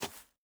Shoe Step Grass Medium E.wav